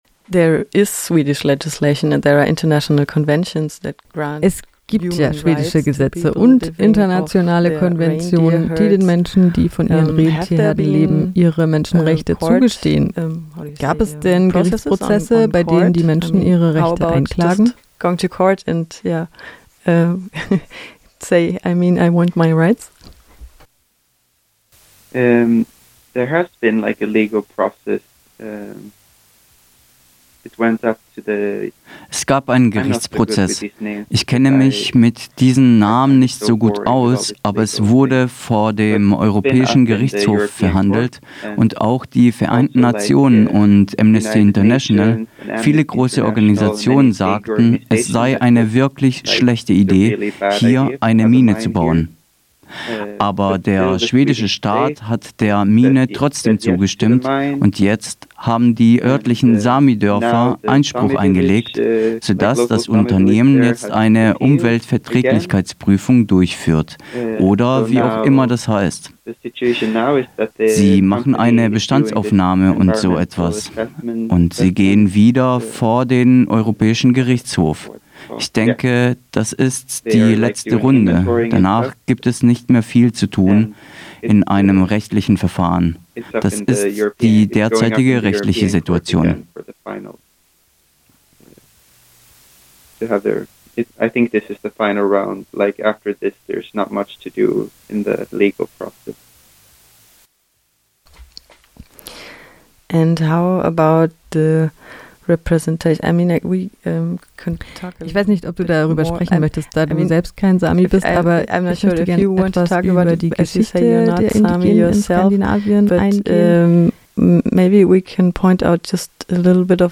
Deutsch: 8:33 Second part in English: 10:07 Zweiter Teil des Interviews übersetzt: 10:07 10:07